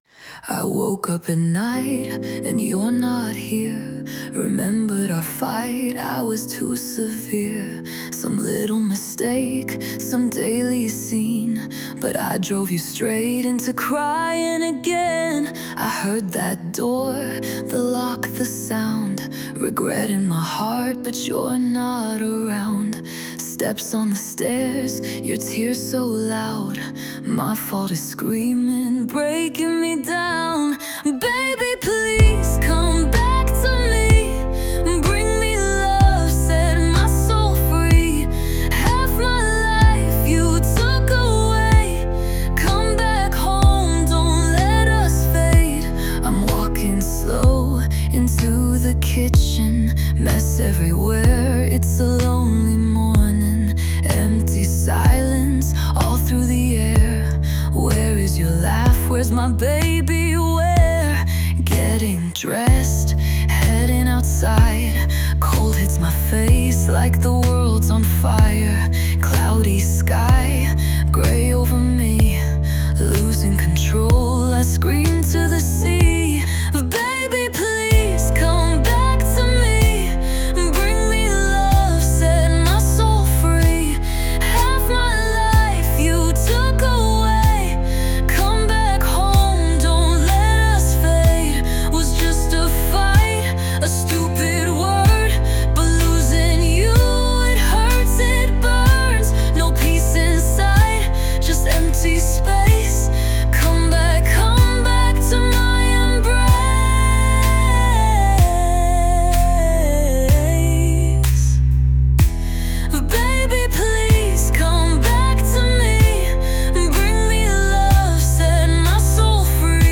English, Soul, Blues, Rock, Country | 13.04.2025 19:15